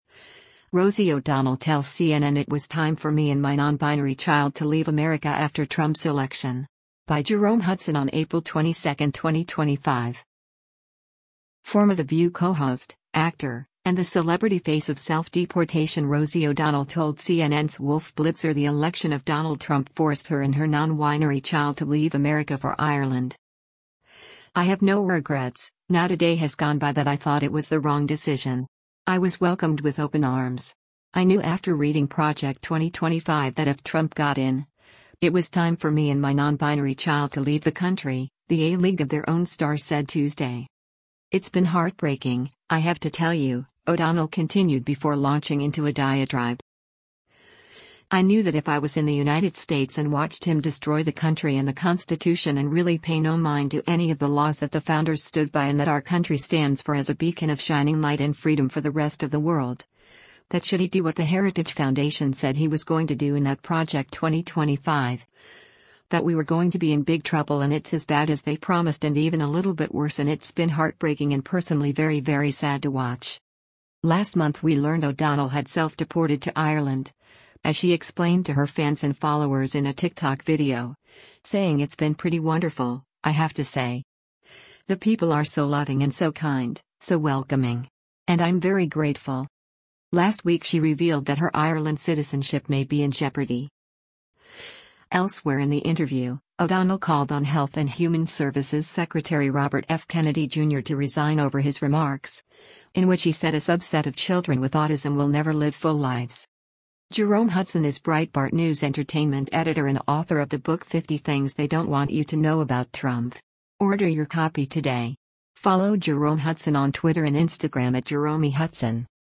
Former The View co-host, actor, and the celebrity face of self-deportation Rosie O’Donnell told CNN’s Wolf Blitzer the election of Donald Trump forced her and her “non-winery child to leave” America for Ireland.